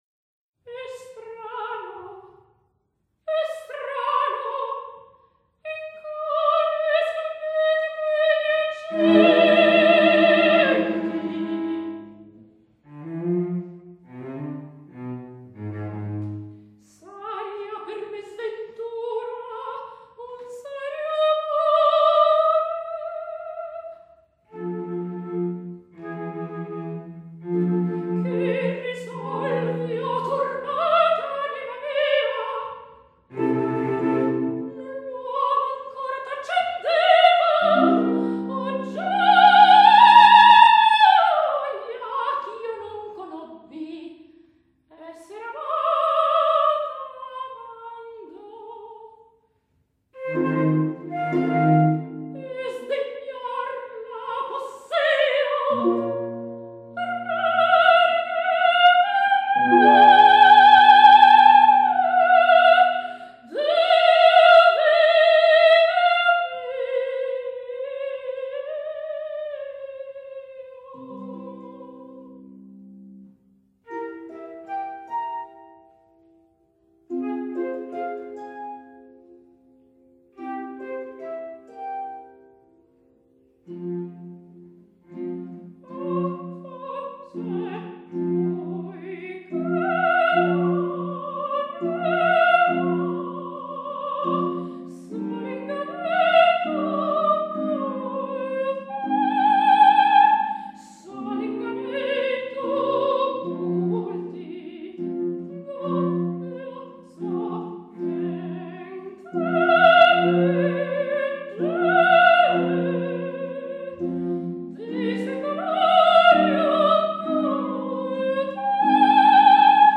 Flauto
Arpa
Violoncello